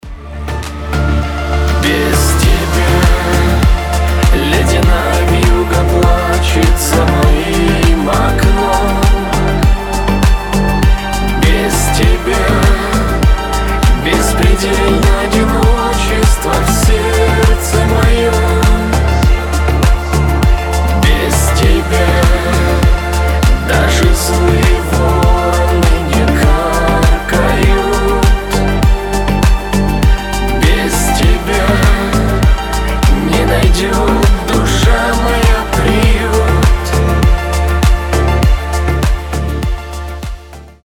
• Качество: 320, Stereo
грустные
холодные